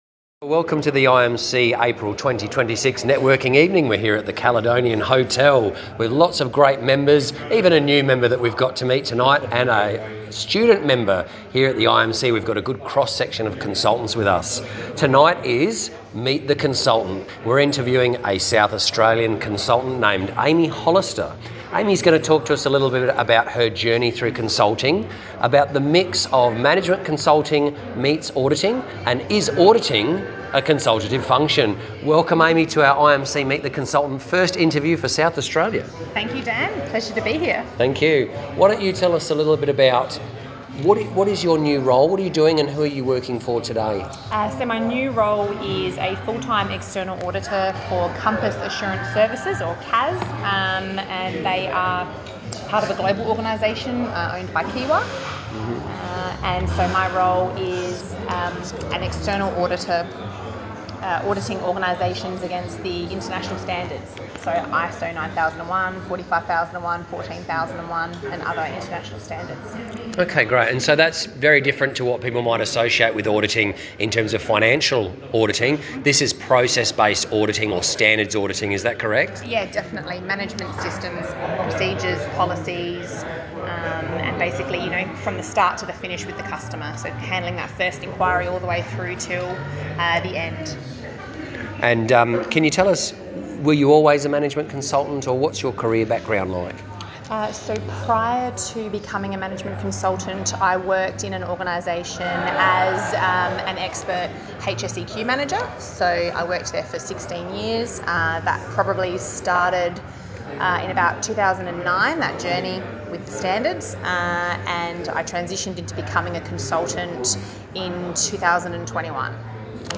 Over food and drinks at the Caledonian Hotel in North Adelaide, Members shared perspectives on emerging trends in management consulting and the growing excitement around this year’s IMC National Conference.
A highlight of the evening was the latest instalment of the Chapter’s ‘Interview a Management Consultant’ series.